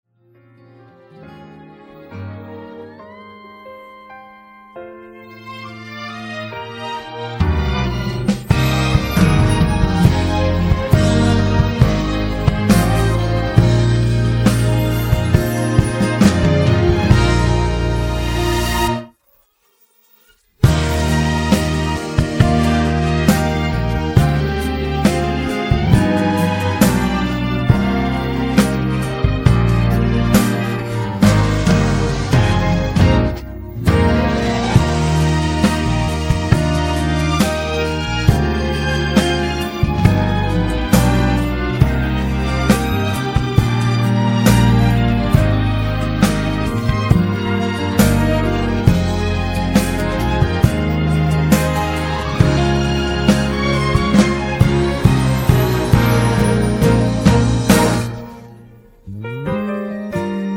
음정 원키 3:38
장르 가요 구분 Voice Cut